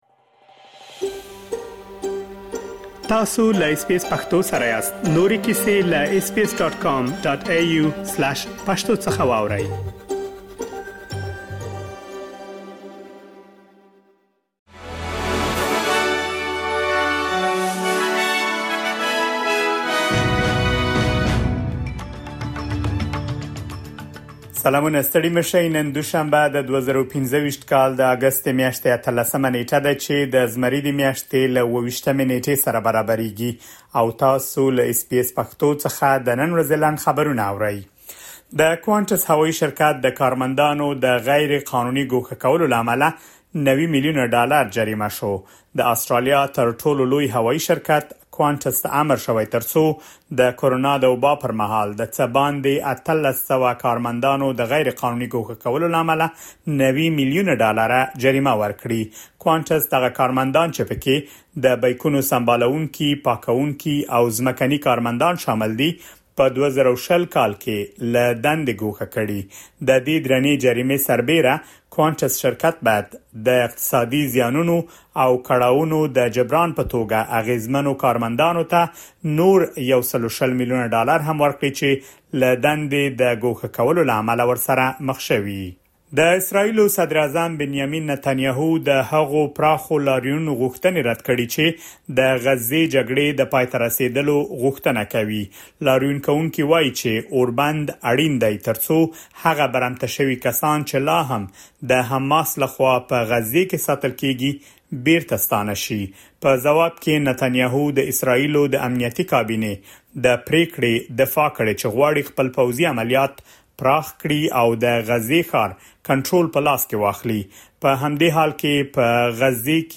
د اس بي اس پښتو د نن ورځې لنډ خبرونه |۱۸ اګسټ ۲۰۲۵
د اس بي اس پښتو د نن ورځې لنډ خبرونه دلته واورئ.